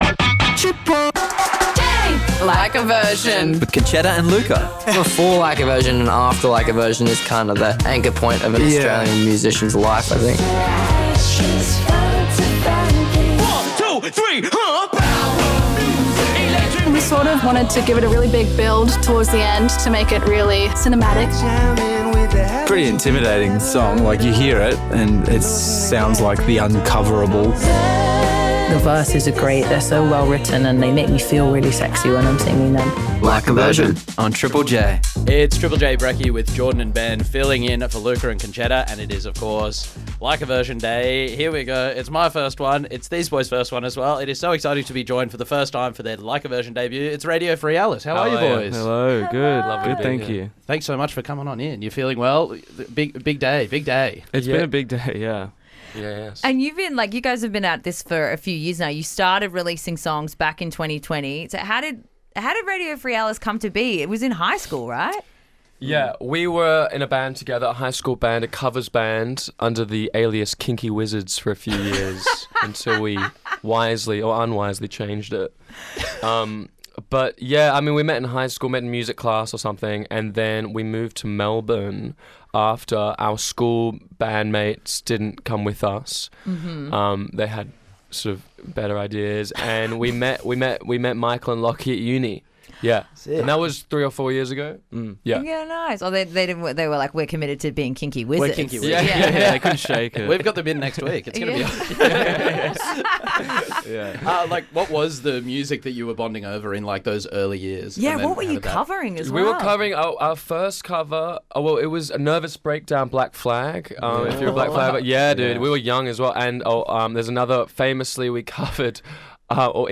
R'n'B